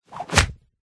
Звуки ударов, пощечин
Удар по телу борьба захват блок или бросок 3